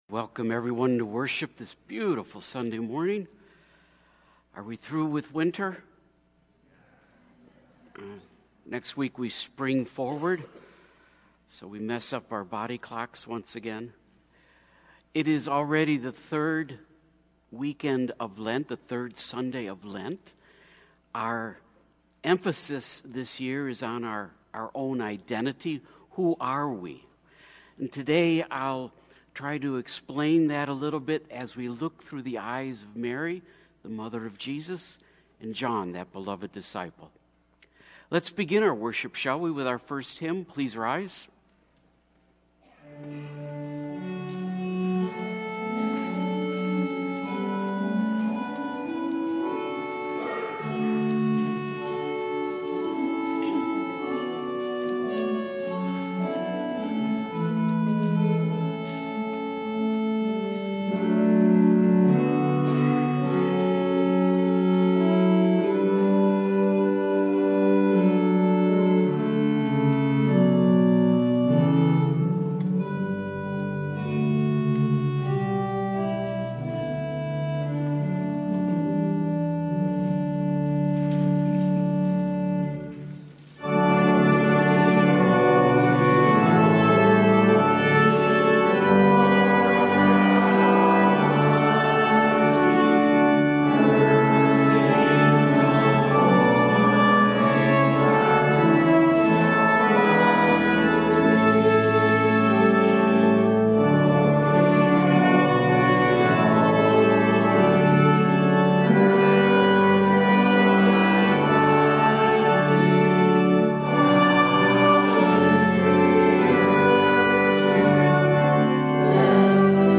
Mar 4 / Divine – Relating to Mary – Lutheran Worship audio